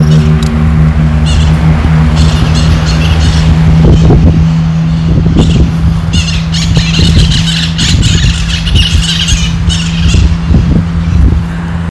White-eyed Parakeet (Psittacara leucophthalmus)
Detailed location: Parque de la ciudad
Condition: Wild
Certainty: Photographed, Recorded vocal